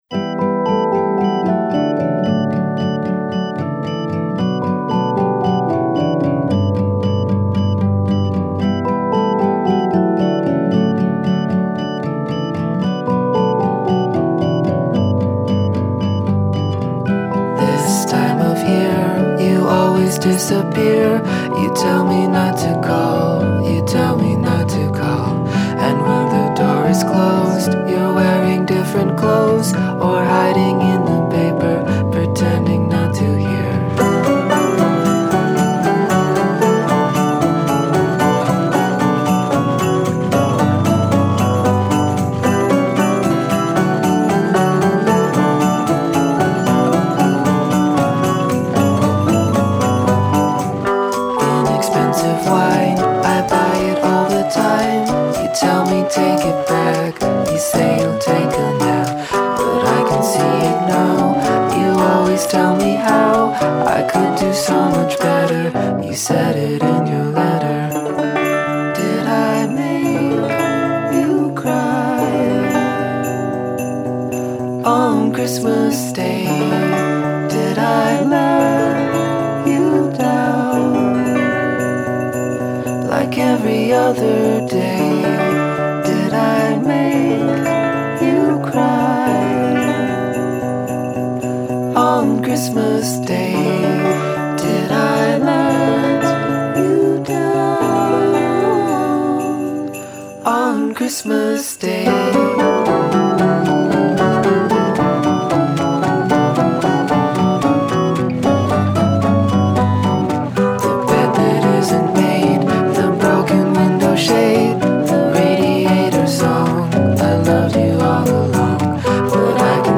My favourite Christmas Song
The song is sad, but also so beautiful.